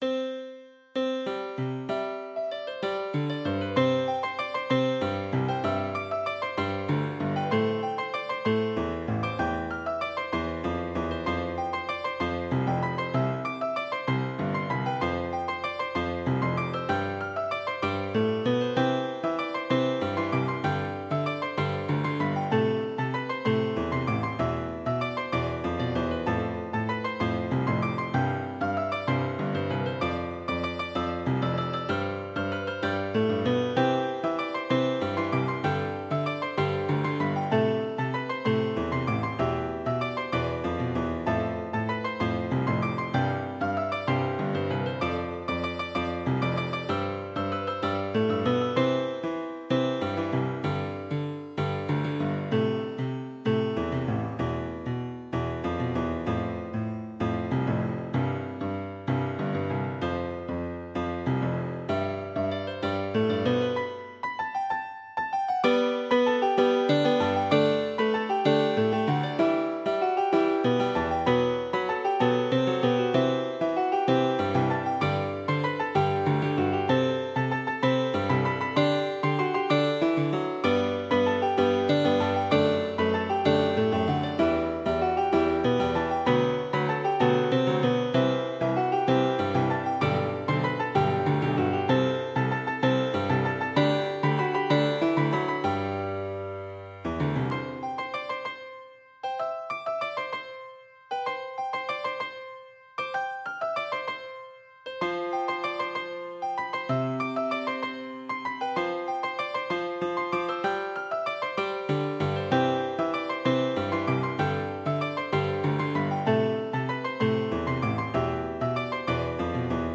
今思うとスウィングの 4/4 拍子です